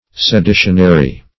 Seditionary \Se*di"tion*a*ry\